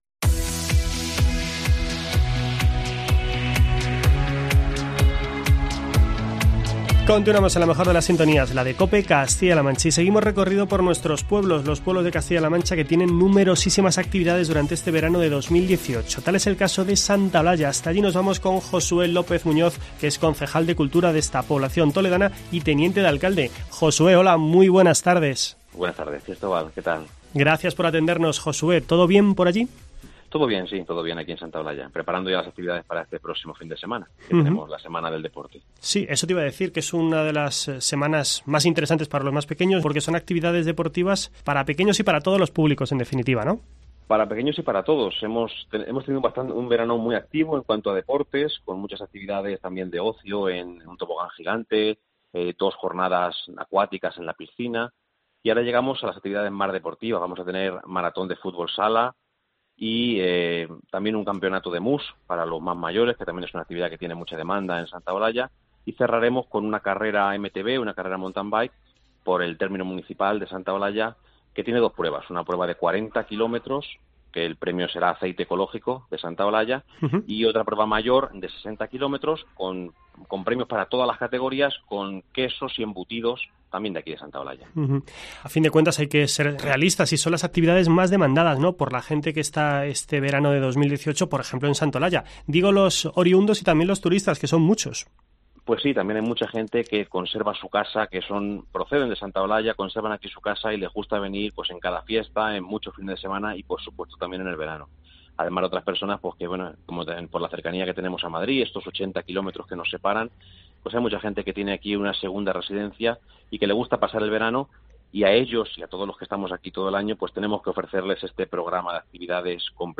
Josué López Muñoz, concejal de Cultura, nos cuenta las numerosas actividades que se están realizando durante el presente verano en la localidad.